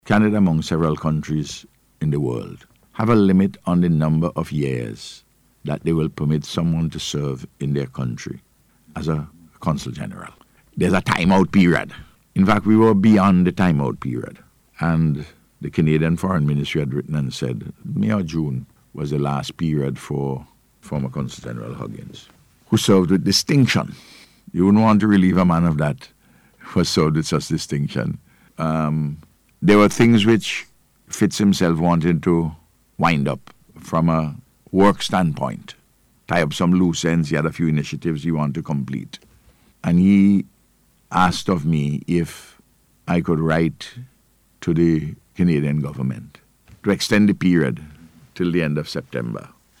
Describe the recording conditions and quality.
He was speaking on NBC’s Face to Face Programme yesterday.